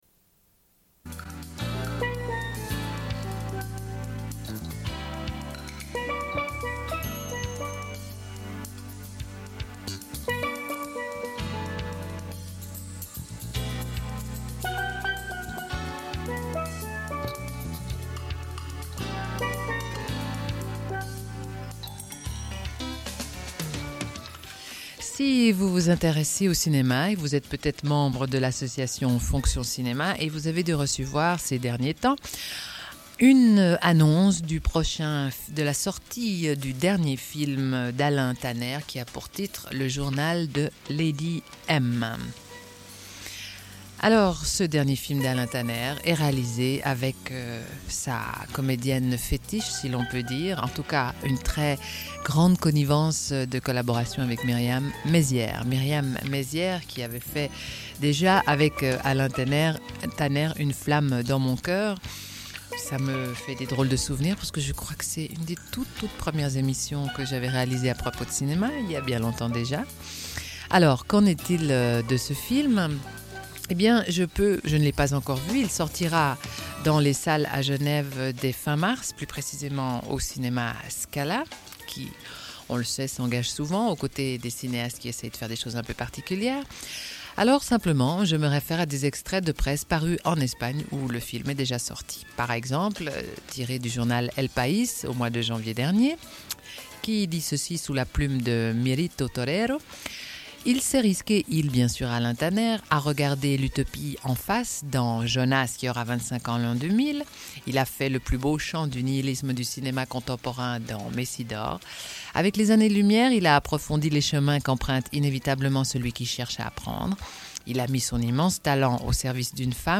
Une cassette audio, face B29:18
Radio